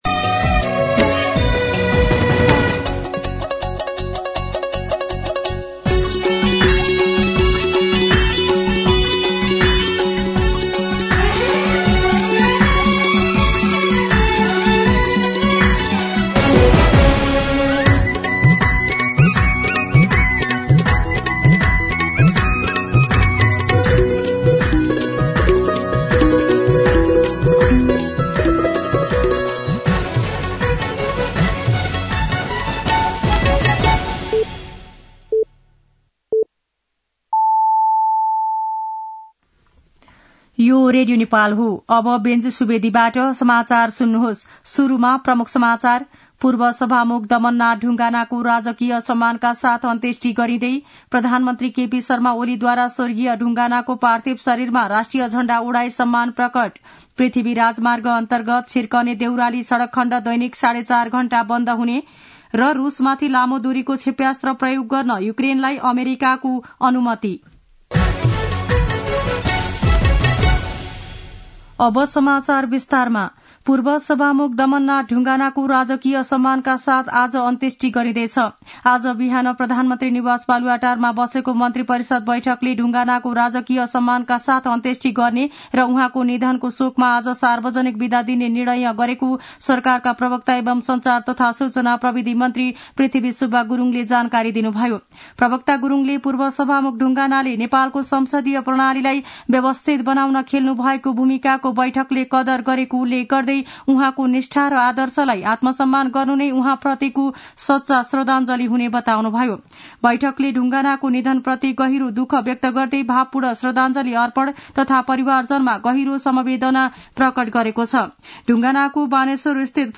दिउँसो ३ बजेको नेपाली समाचार : ४ मंसिर , २०८१
3-pm-nepali-news-1-2.mp3